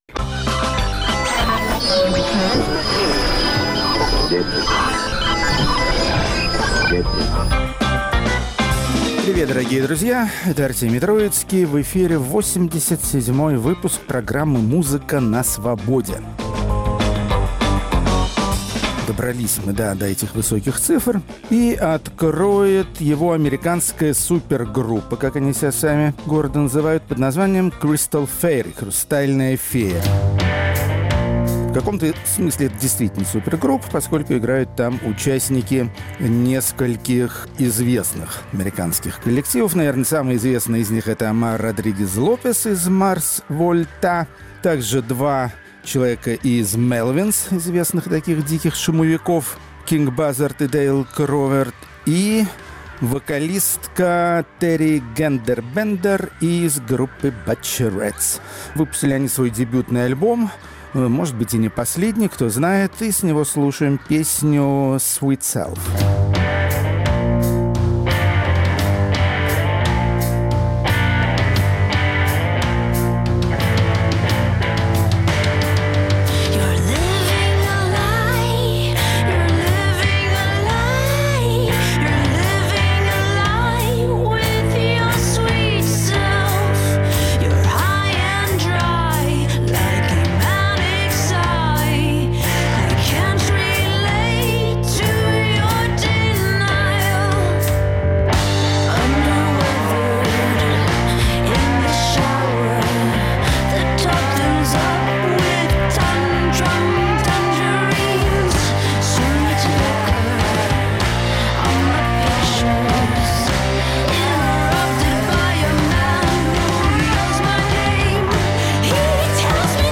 Зажигательные бразильские музыканты, которые исполняют не только румбу и самбу. Рок-критик Артемий Троицкий приобрел для своей коллекции записи, которые позволяют по-иному оценить пути развития тропической музыки.